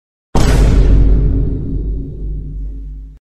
Vine Boom Effect